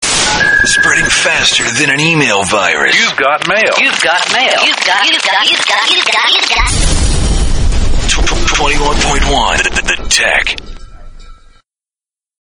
RADIO IMAGING / HOT AC